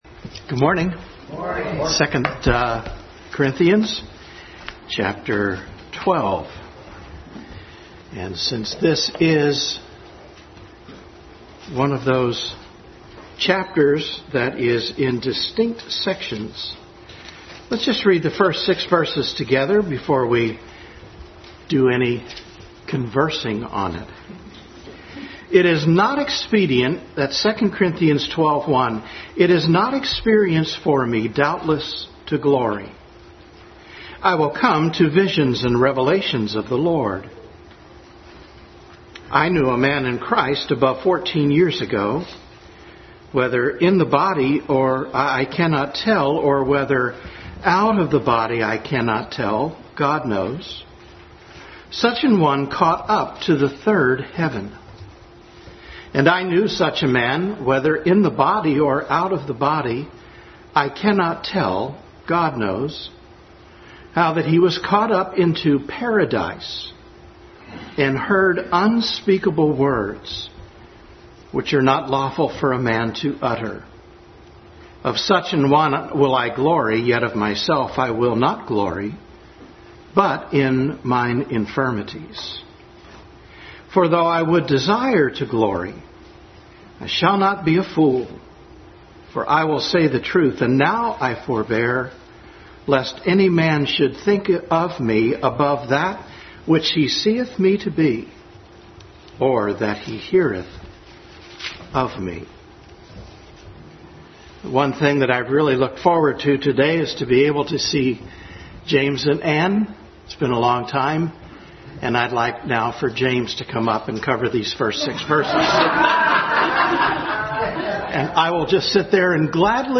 Adult Sunday School Class continued study in 2 Corinthians.